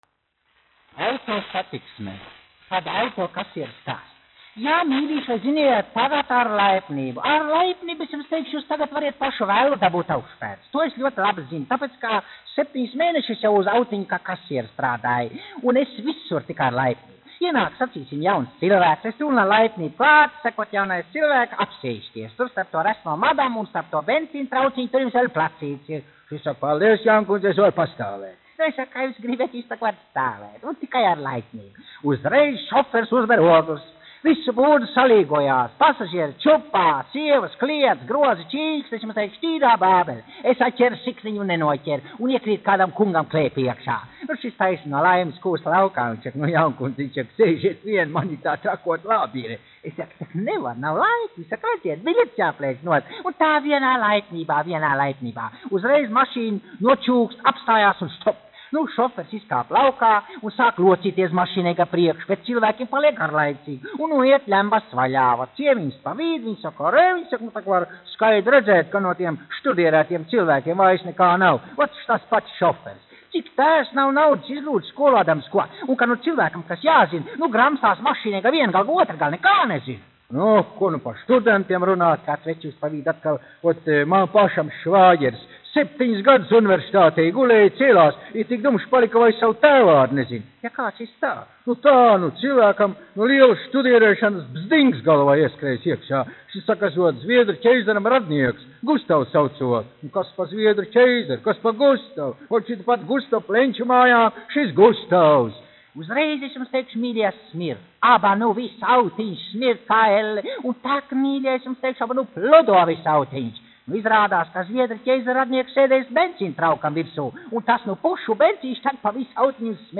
Auto satiksme : humoristisks stāstiņš
1 skpl. : analogs, 78 apgr/min, mono ; 25 cm
Skaņuplate
Latvijas vēsturiskie šellaka skaņuplašu ieraksti (Kolekcija)